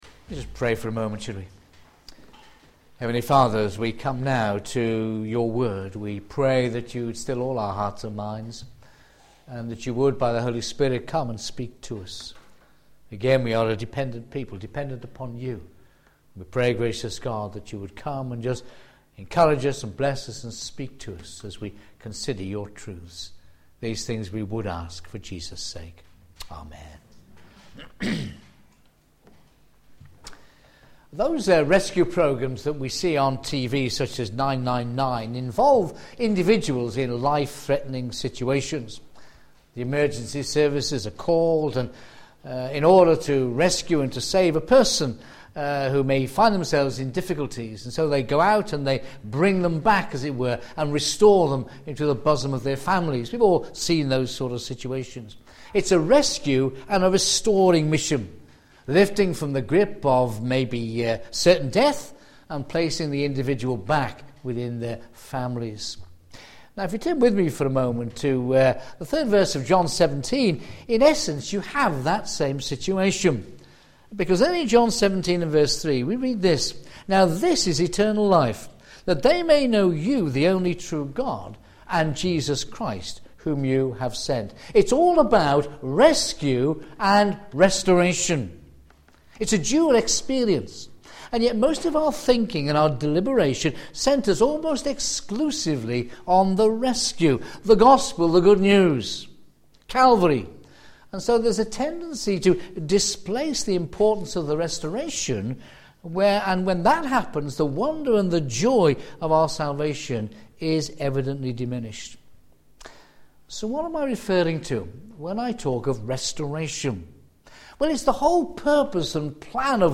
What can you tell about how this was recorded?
Media for a.m. Service on Sun 27th Feb 2011 10:30